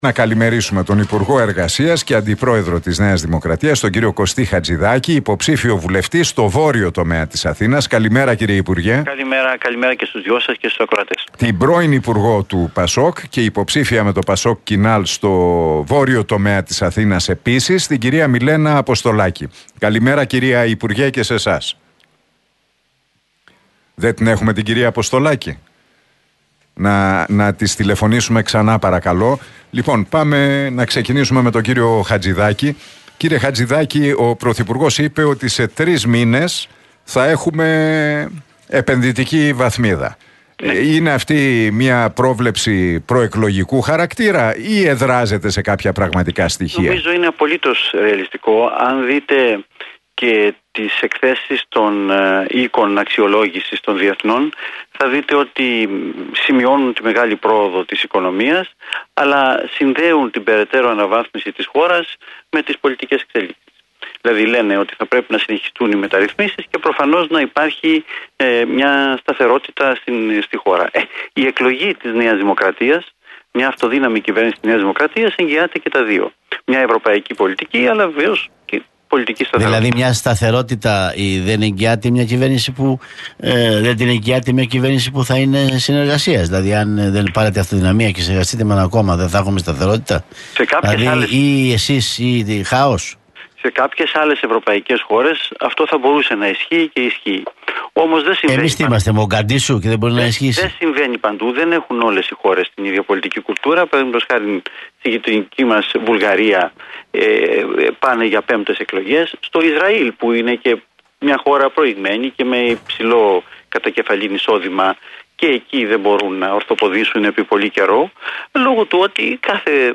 Εκλογές 2023: Debate Χατζηδάκη - Αποστολάκη στον Realfm 97,8